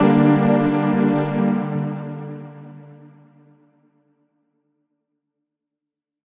Chords_G_02.wav